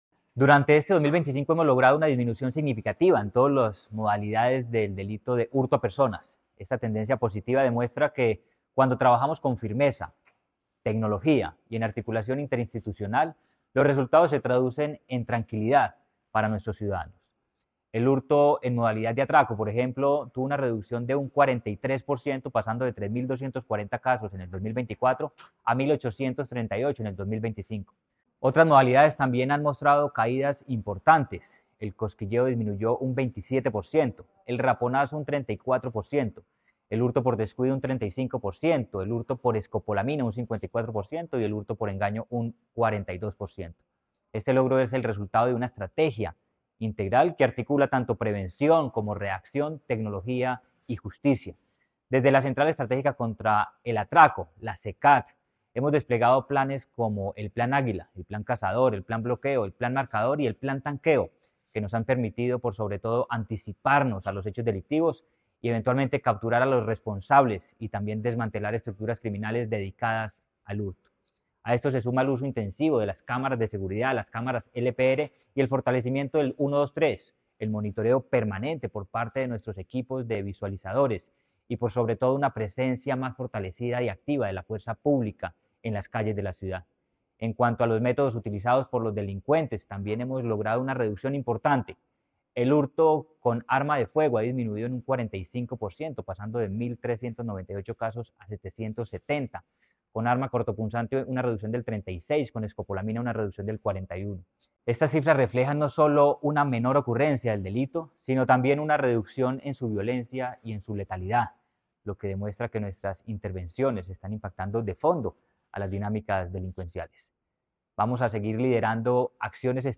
Audio-declaraciones-secretario-de-Seguridad-y-Convivencia-Manuel-Villa-Mejia-01.mp3